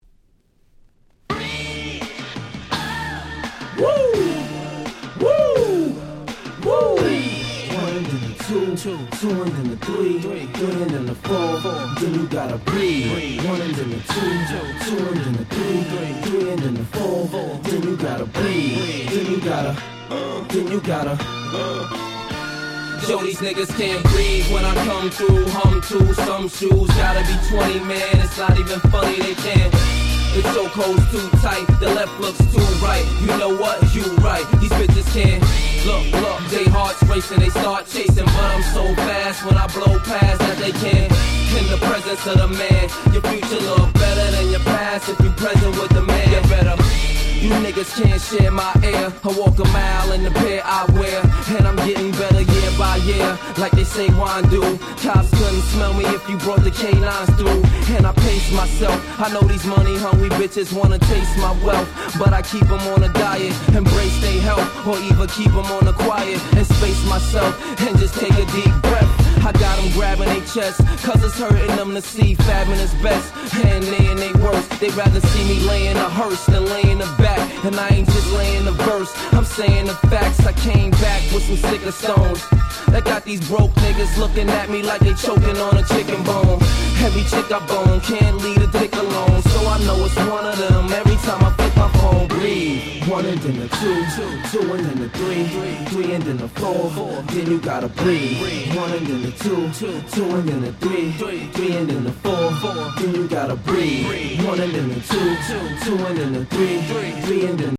05' Super Hit Hip Hop !!